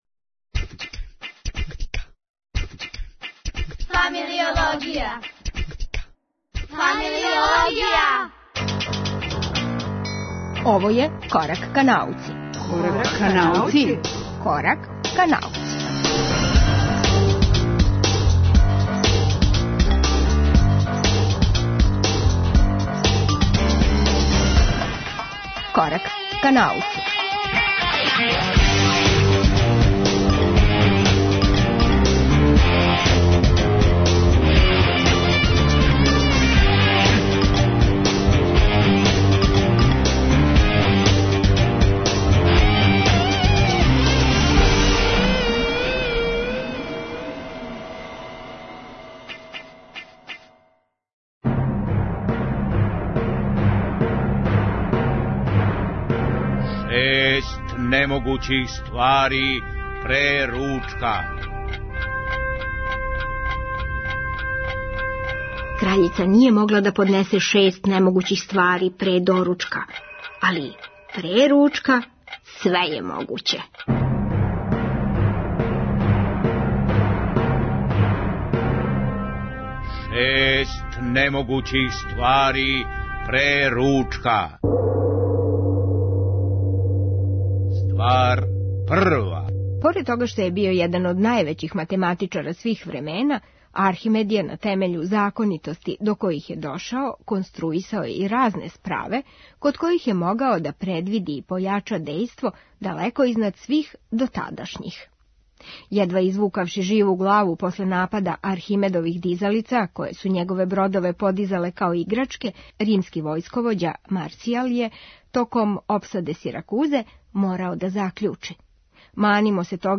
Овај Корак ка науци чини шест разговора - о математици, информатици, хемији, геологији, екологији и календарима - и шест немогућих ствари пре ручка.